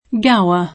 Gower [ingl.
g# o